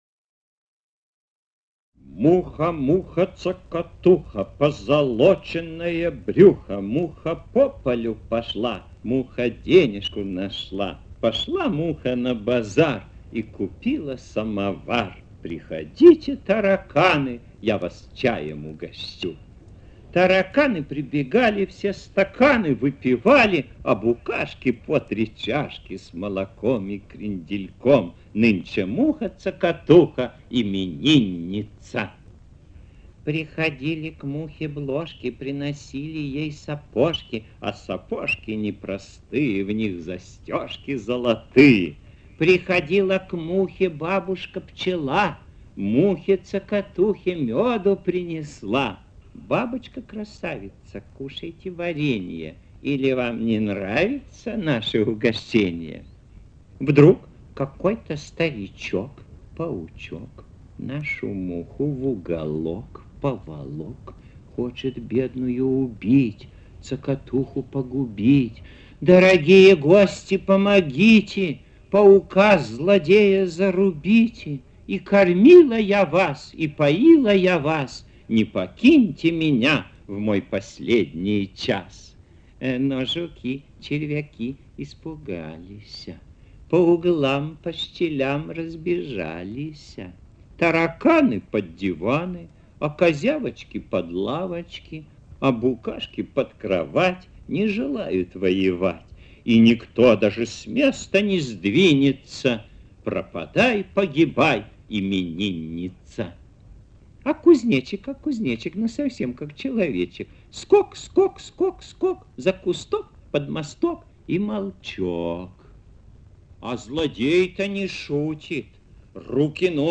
«Муха-Цокотуха» в исполнении автора
«muha-tsokotuha»-v-ispolnenii-avtora.mp3